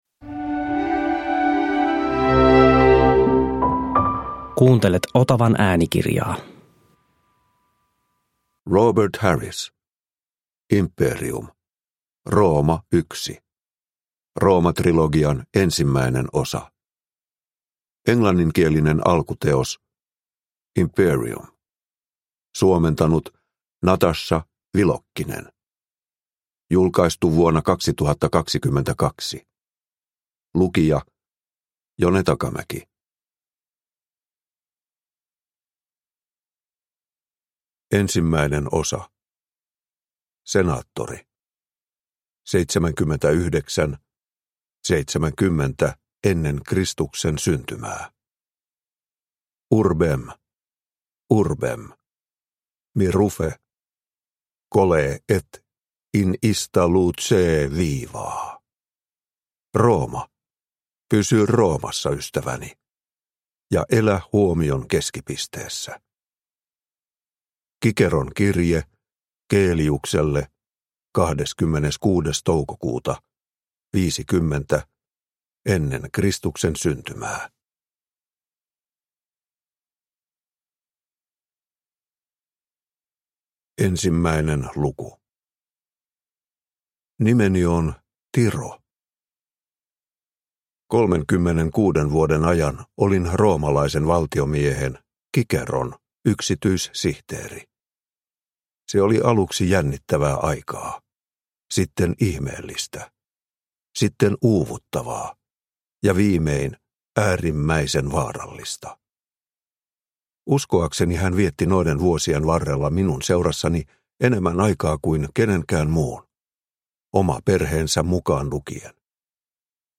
Imperium – Ljudbok – Laddas ner